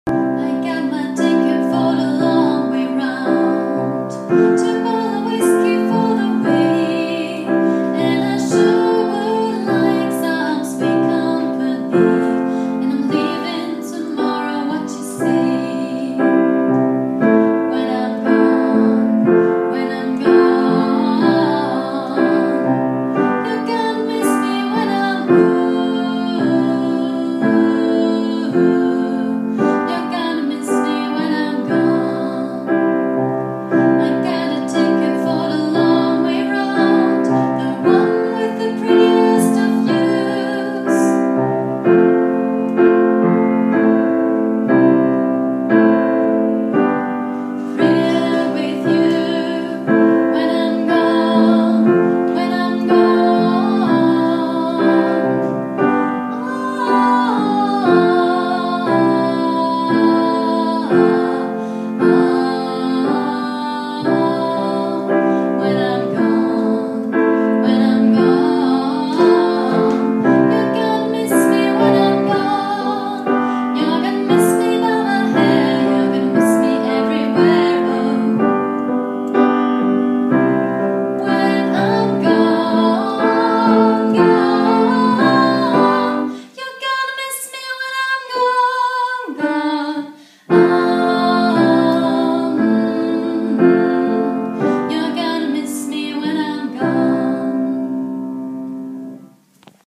Übungsdatei 1. Stimme
6_cups_1stimme.mp3